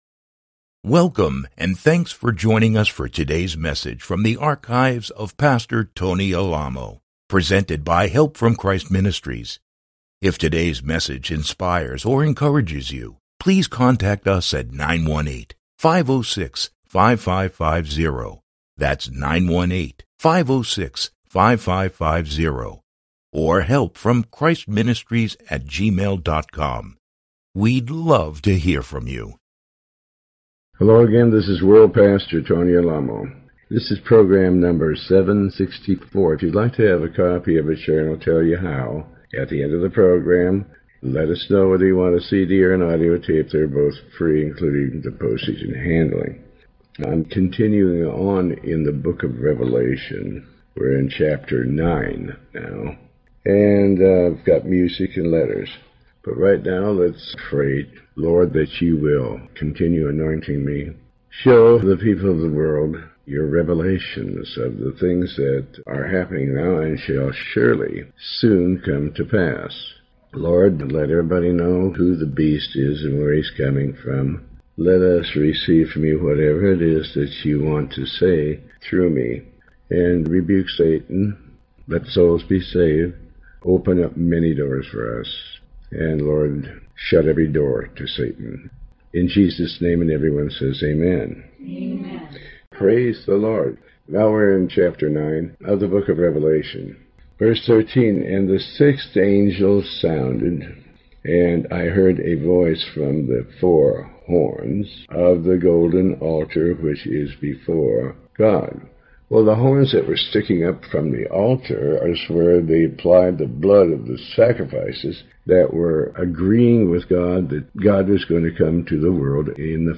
Pastor Alamo reads and comments on the Book of Revelation chapters 9. This program is part of a series covering the entire Book of Revelation